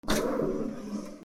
自動扉 ビル ガラス製 01 開く音
/ K｜フォーリー(開閉) / K52 ｜ドア－セット / law_扉(仮)